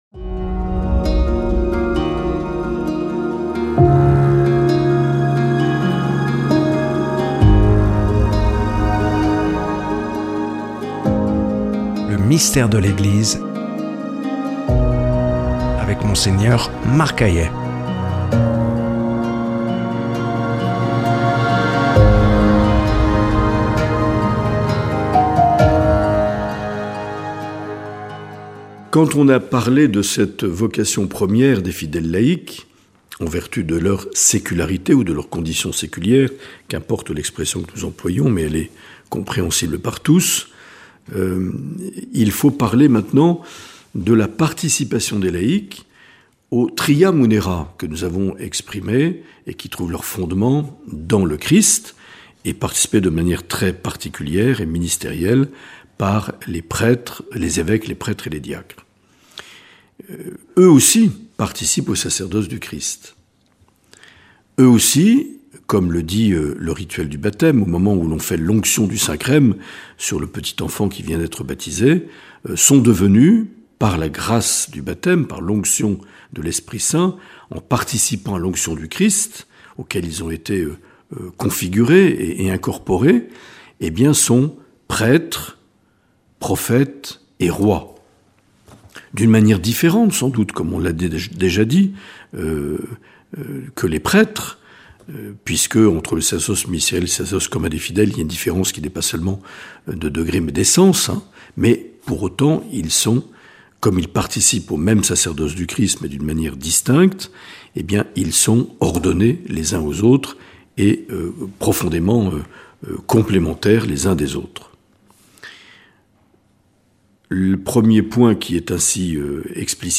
Monseigneur Marc Aillet
Présentateur(trice)